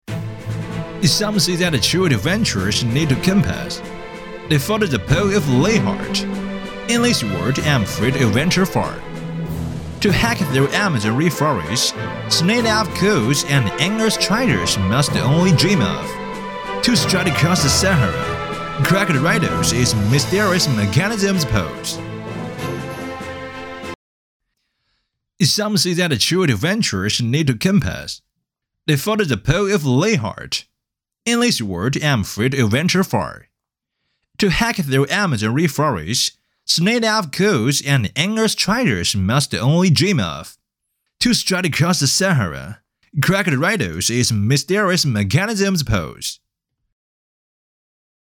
男678--英文-冒险家-英-信息流.mp3